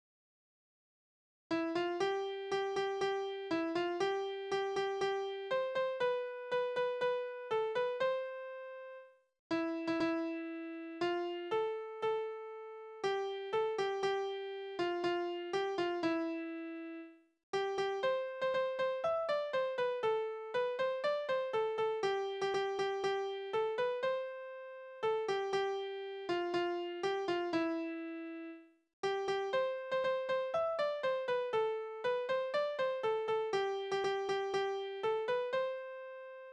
Balladen: Selbstmord der Verführten